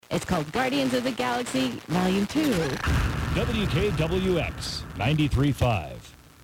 recording Es - "WKWX" - 783 miles
Es - Sporadic E-Skip
Receptions in St. Cloud are made with a variety of radios, more than likely a Sony XDR-F1HD, Grundig S350, Grundig G8, or the stock radio of a 2010 Subaru Impreza (Outback edition) with RDS.